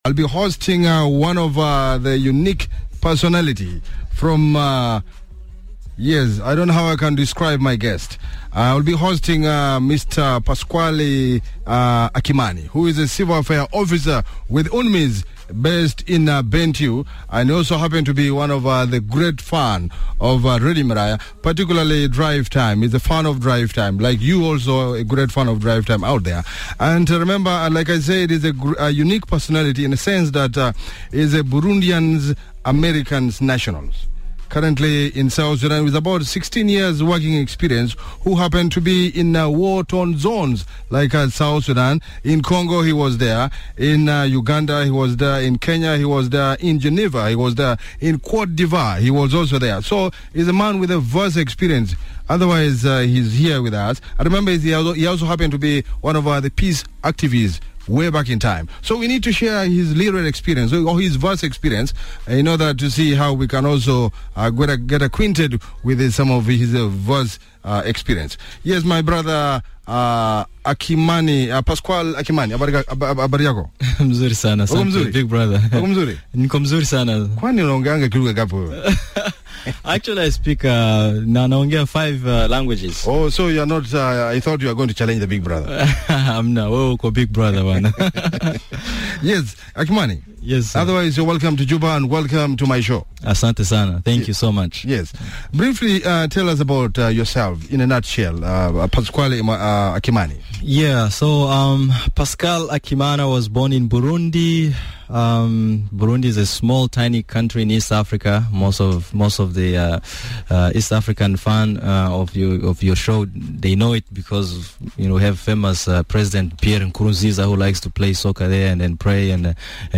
Radio Miraya / Drive Time Lively Conversation